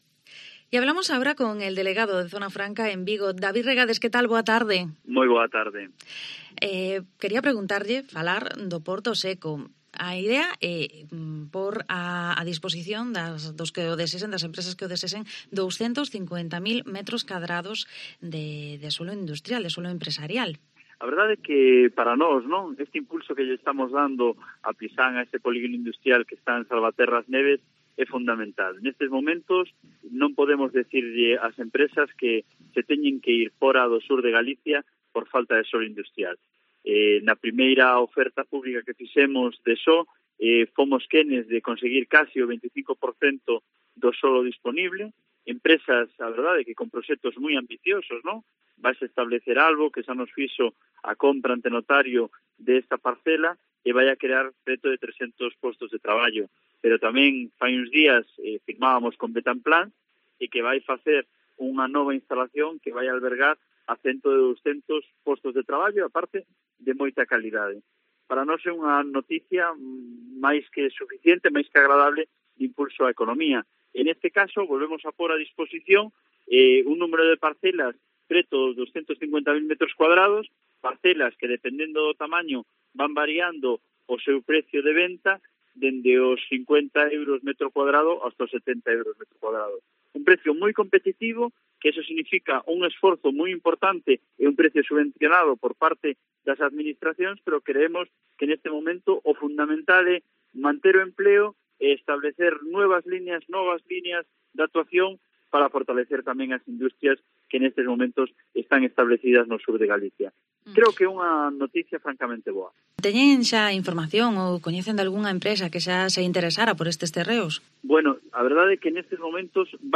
ENTREVISTA
Hablamos con el Delegado de Zona Franca, David Regades sobre el puerto seco y también sobre la futura apertura de una escuela infantil en el polígono de A Granxa en Porriño.